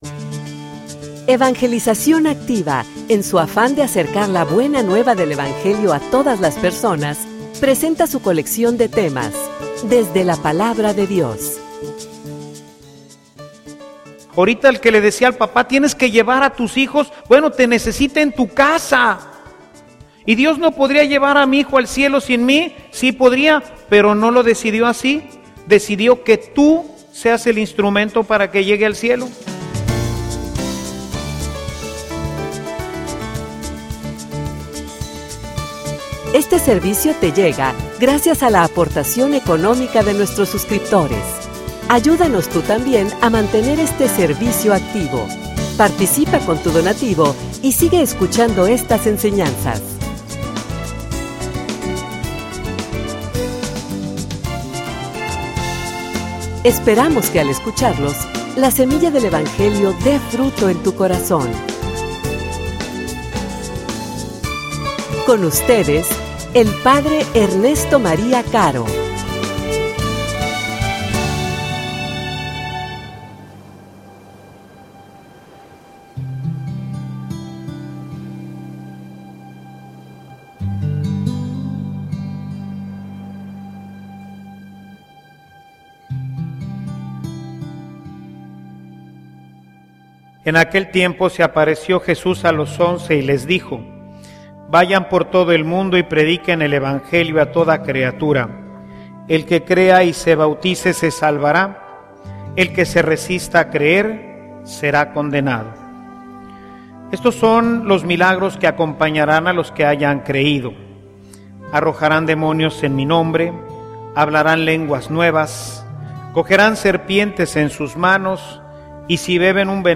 homilia_Cuento_contigo.mp3